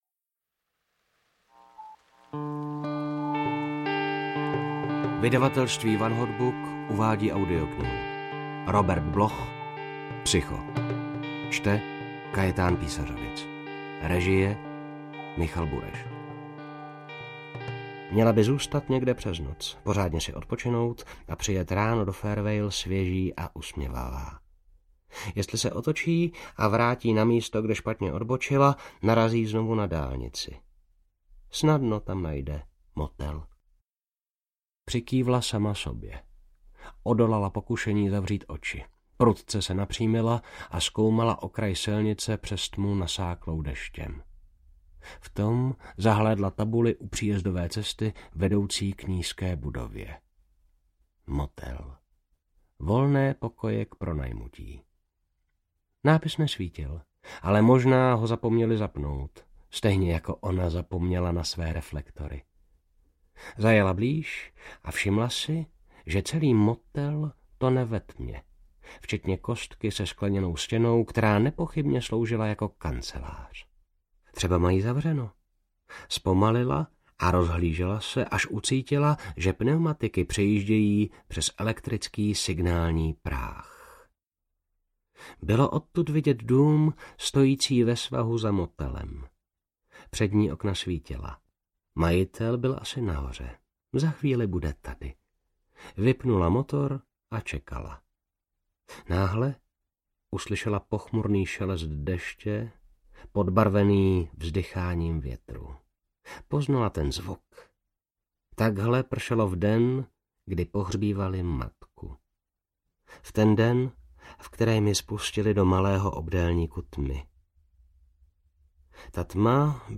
Psycho audiokniha
Ukázka z knihy